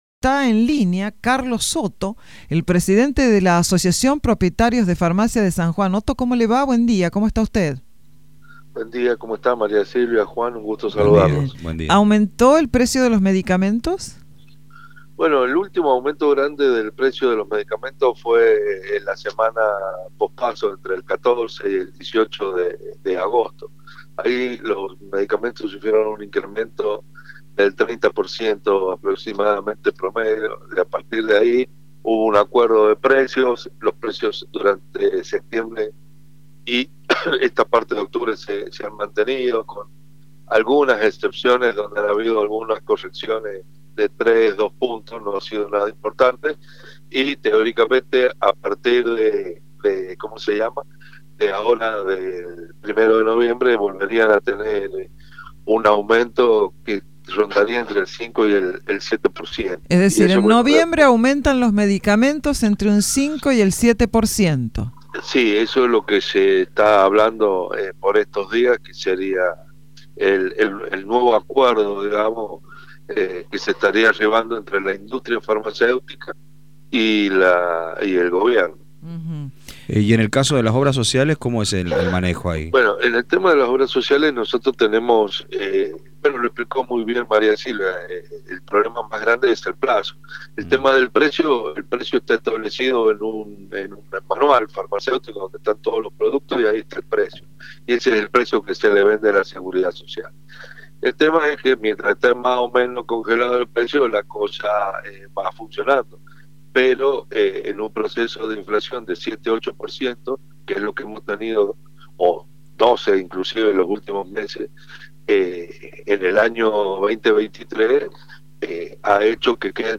en comunicación con Radio Sarmiento